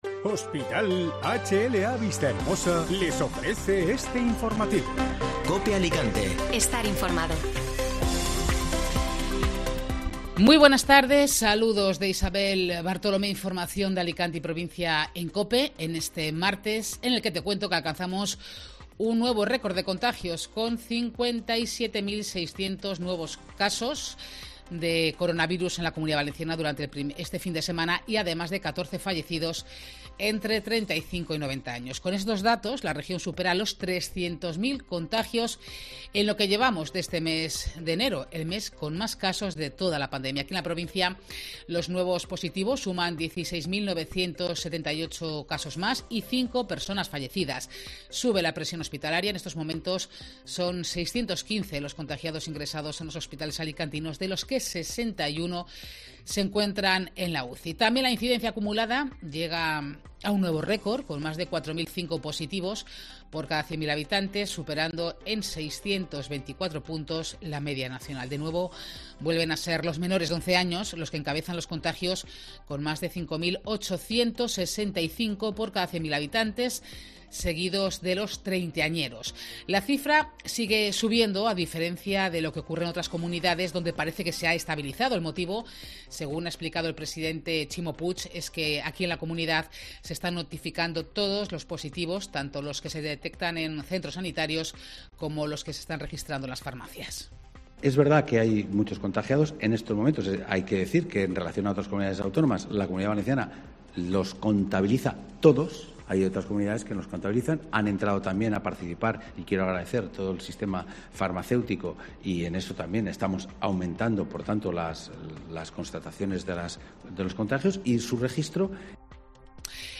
Informativo Mediodía COPE (Martes 25 de enero)